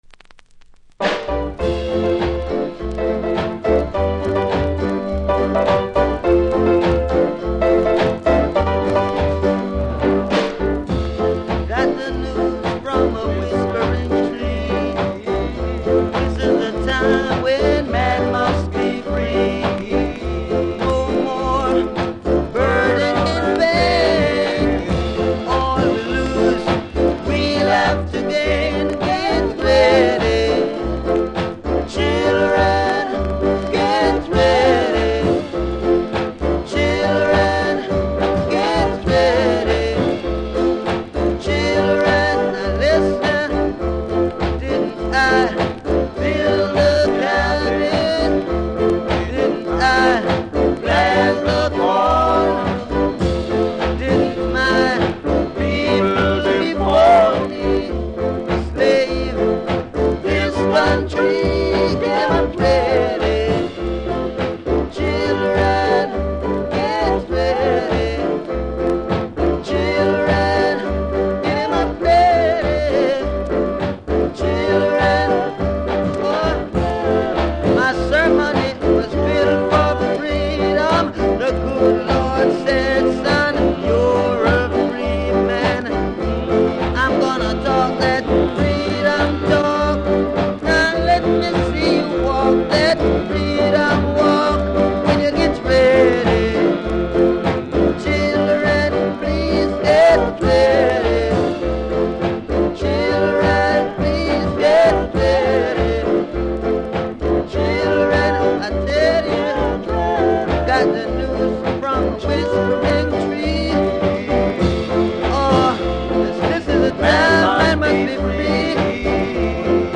両面ノイズはそこそこありますがプレイは問題無いレベルだと思いますので試聴で確認下さい。
盤に歪みありますがプレイは問題無いレベル。